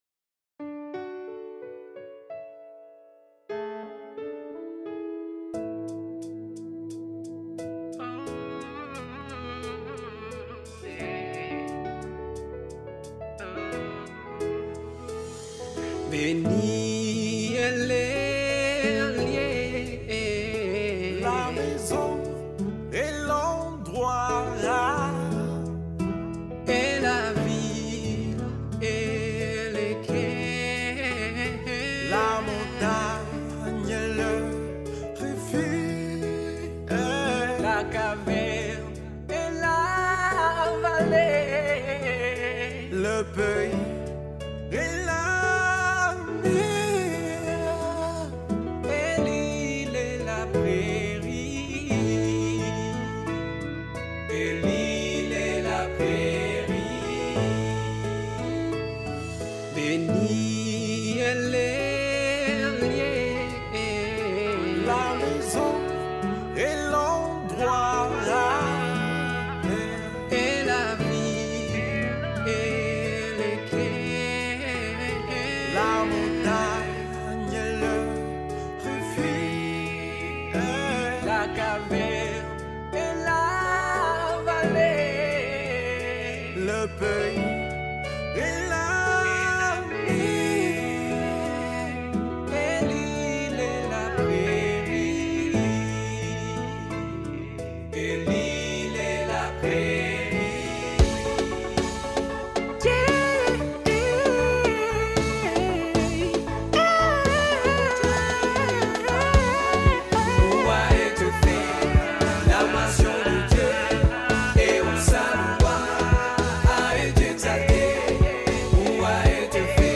A musical group in Kinshasa, Democratic Republic of the Congo, pays homage to the Báb through compositions of songs highlighting subjects such as progressive revelation, the sacredness of the Báb, and submission to the Will of God. One of the compositions is the well-known prayer that begins, “Blessed is the spot.”